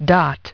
dot – [ dot ] – / dɒt /